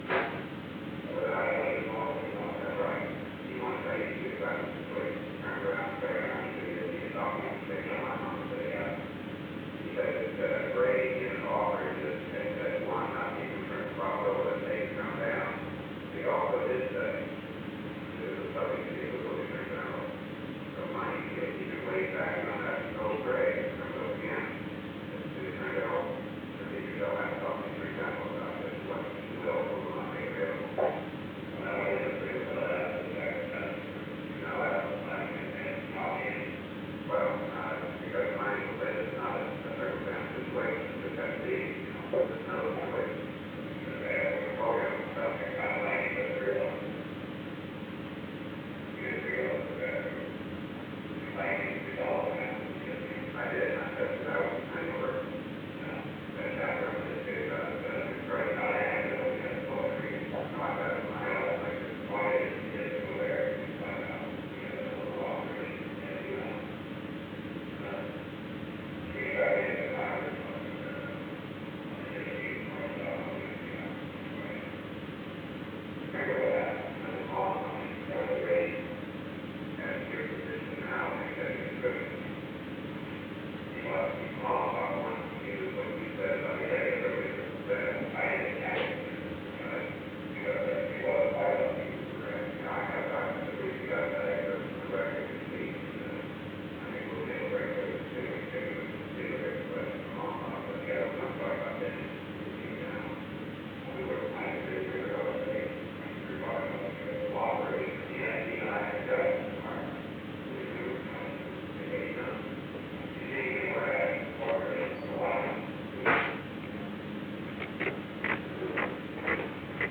Location: Executive Office Building
The President listened to a portion of a recording of a meeting with John W. Dean, III.
The President stopped the recording at 12:51 pm.
An unknown person entered at an unknown time after 12:35 pm.